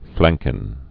(flăngkən)